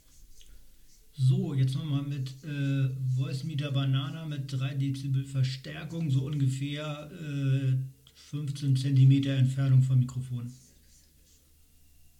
Beyerdynamic M70 Pro X Mikrofon an Motu M2 und Rode AI-1 Klang enttäuschend trotz Gain und Vorverstärker
Habe mal ein wenig im Voicemeter rumgespielt und 3db erhöht.